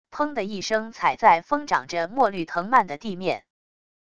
砰的一声踩在疯长着墨绿藤蔓的地面wav音频